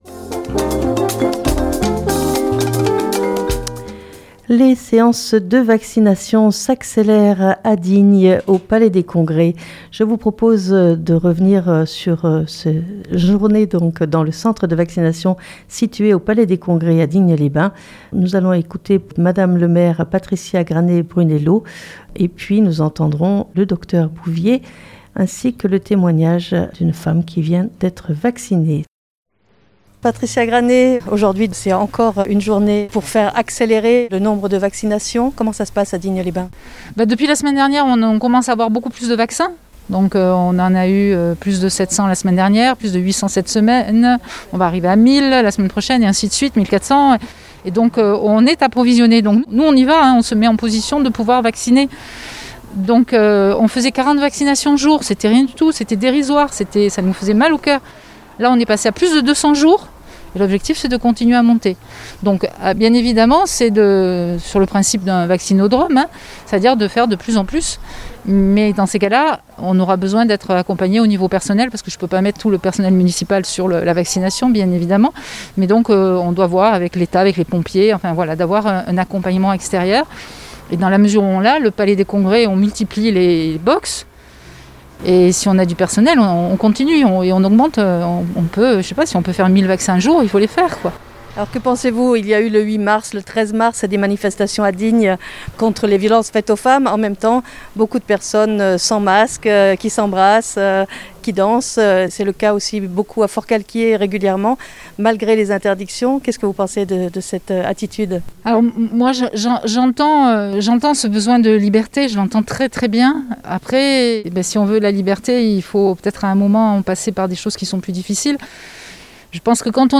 Patricia Granet-Brunello Maire de Digne, fait le point sur la situation
Reportage